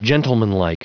Prononciation du mot gentlemanlike en anglais (fichier audio)
Prononciation du mot : gentlemanlike